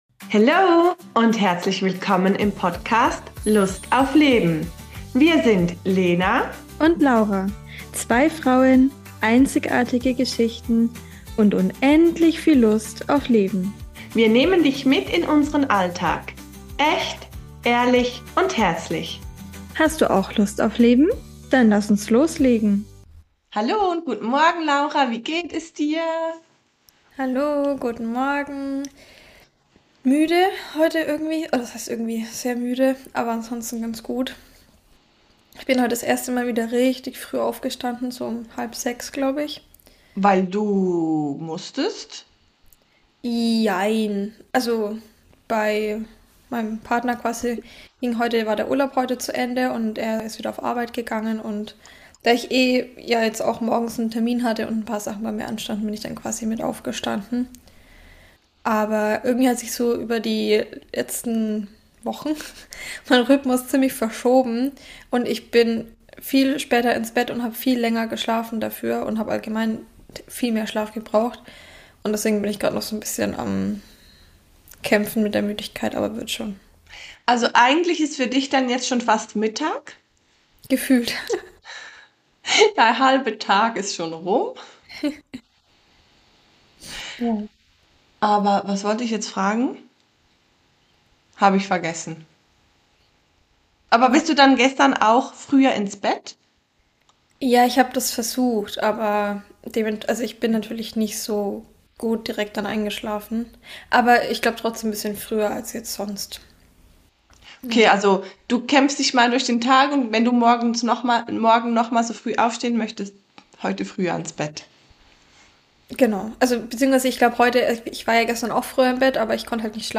In dieser Folge starten wir ehrlich, müde und mit viel Lachen ins neue Jahr.